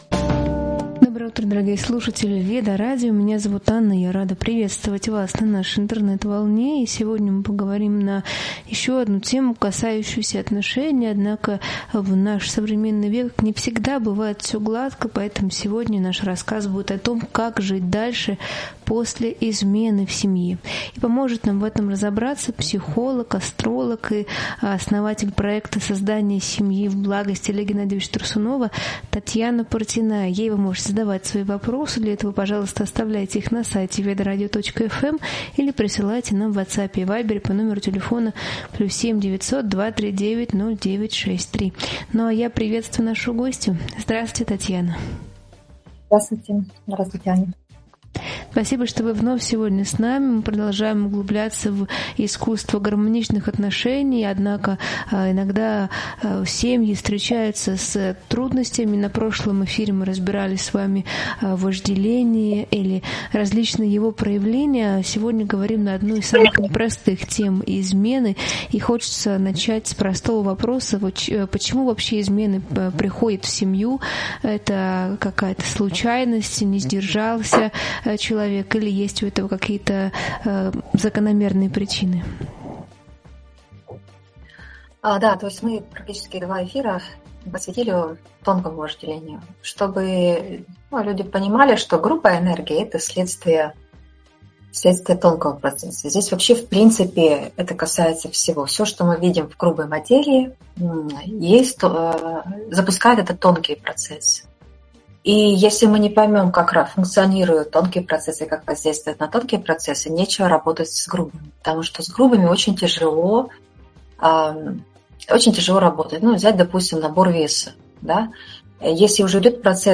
В этом эфире обсуждается, как пережить измену и сохранить внутреннюю опору. Разбираются причины измен, их эмоциональные и кармические последствия, влияние кризисов на семью и детей. Говорится о роли ответственности, прощения, честного диалога и духовного подхода в исцелении отношений.